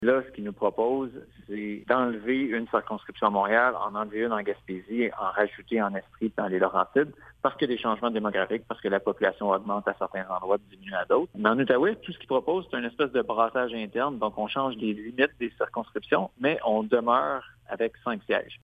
Le député libéral déplore que la Commission envisage la création de nouvelles circonscriptions dans d’autres régions et d’un simple remaniement en Outaouais :